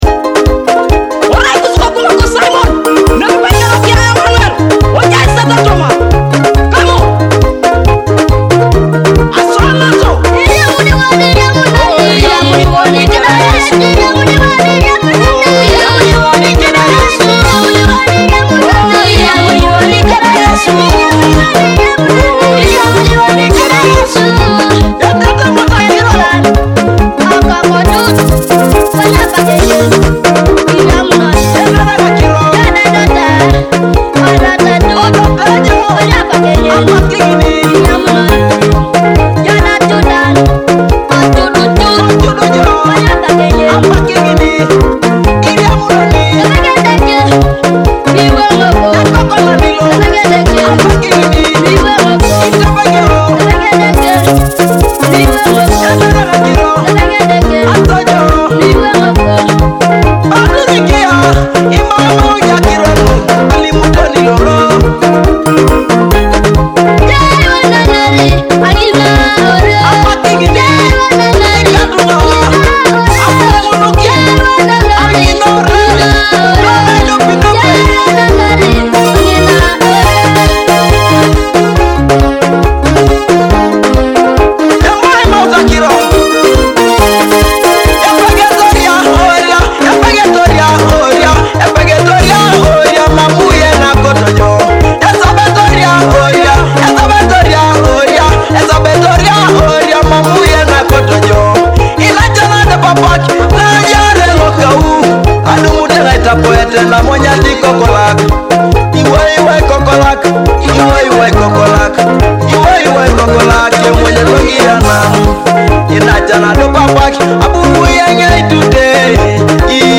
an inspiring Teso gospel anthem.
gospel hit